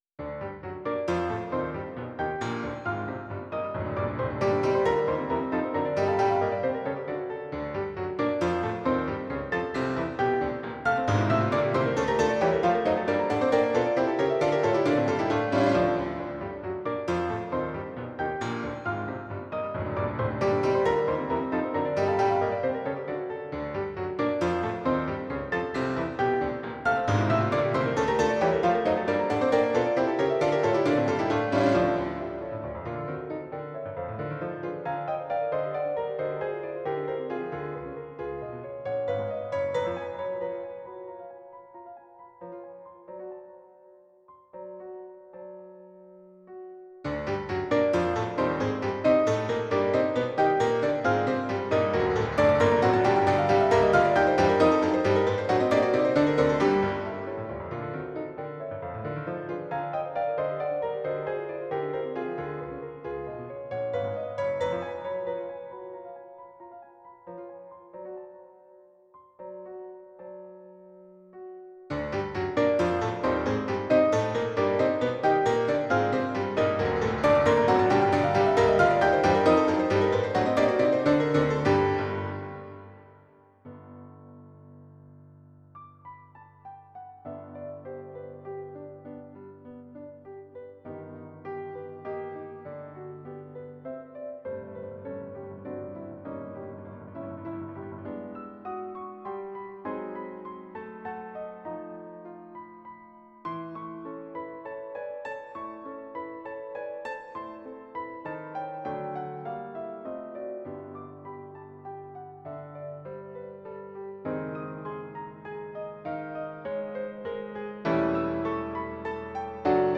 Bruckner.-Symphony-no.-1-Early-Scherzo_-midi-arr.-for-four-pianos.wav